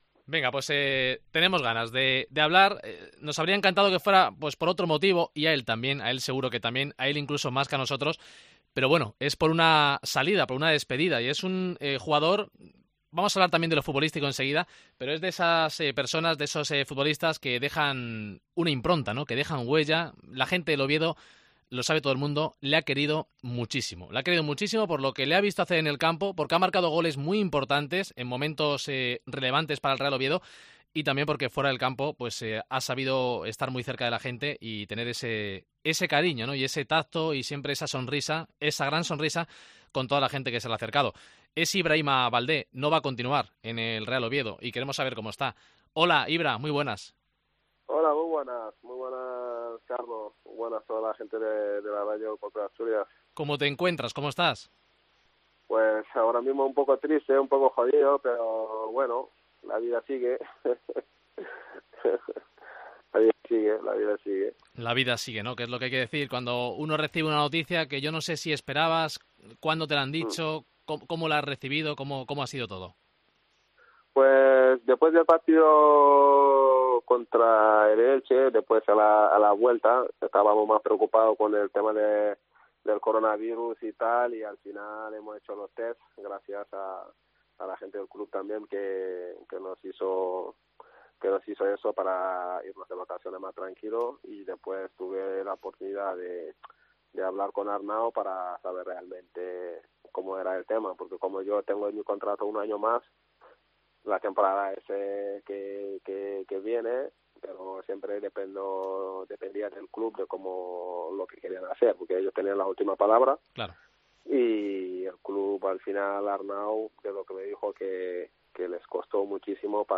Entrevista a Ibrahima Baldé en DCA